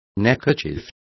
Complete with pronunciation of the translation of neckerchief.